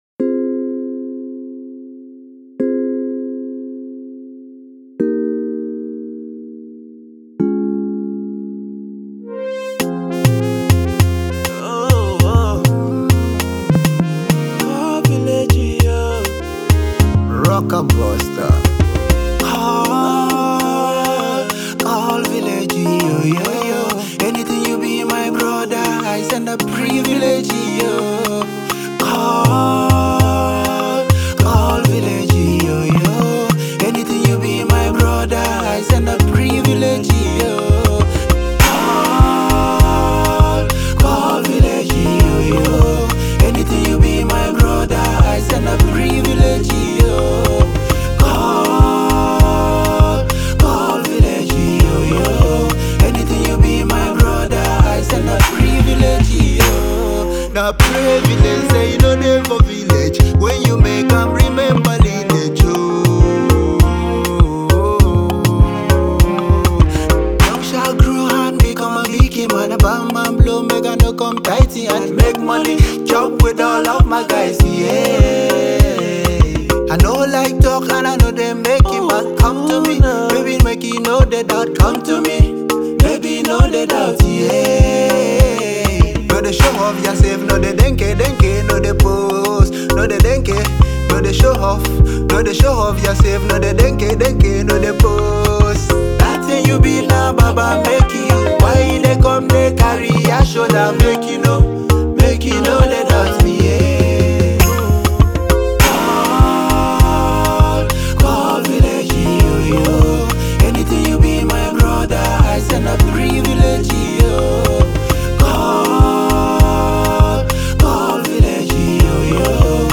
conscience awakening theme song